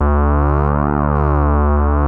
OSCAR D2  5.wav